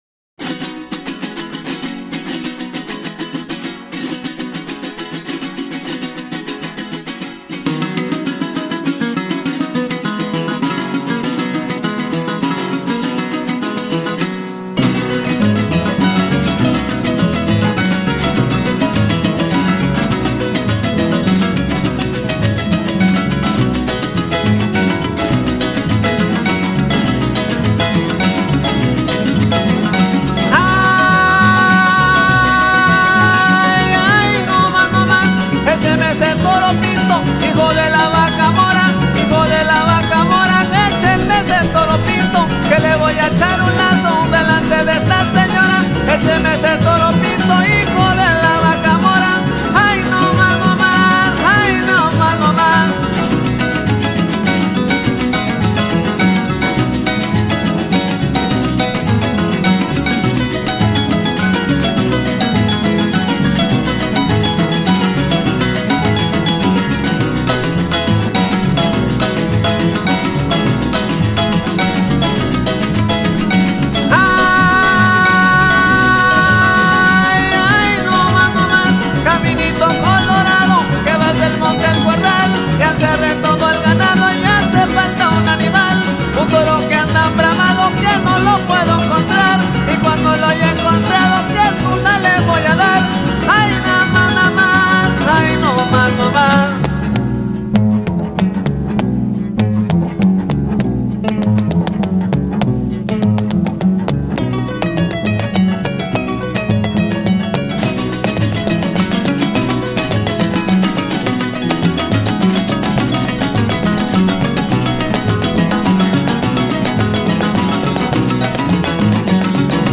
(harpist, director)
(jarana and zapateado performer, vocals)
(harpist, lead vocalist)
(requinto jarocho, vocals)